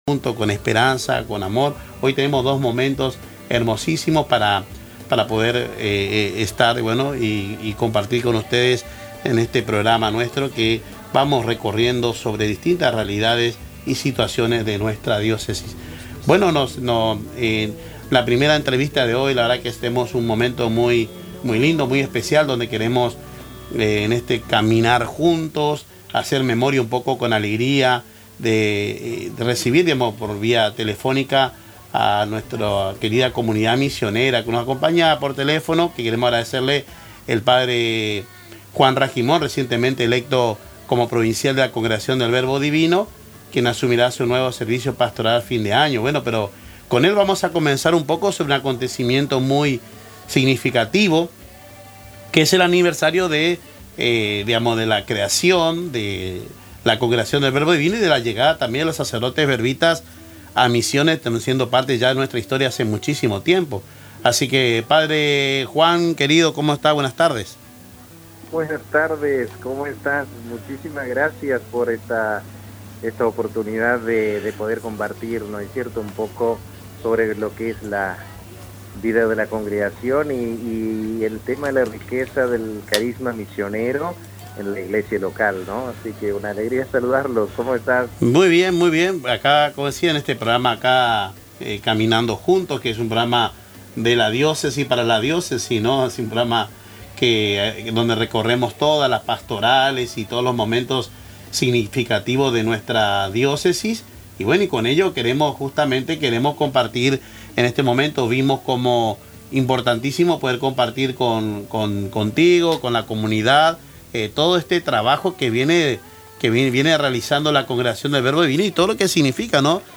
La entrevista repasó la rica trayectoria de la congregación en la provincia, la llegada de los primeros sacerdotes verbitas y la labor pastoral y educativa desarrollada a lo largo de más de un siglo.